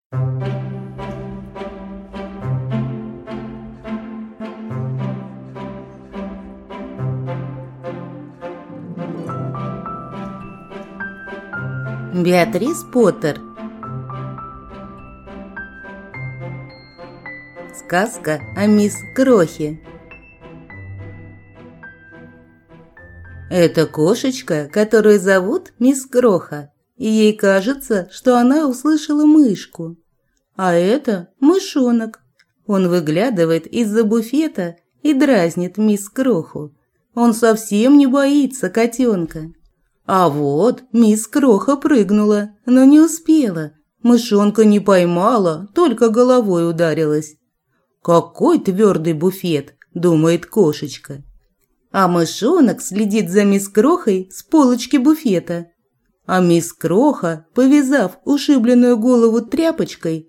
Аудиокнига Сказка о мисс Крохе | Библиотека аудиокниг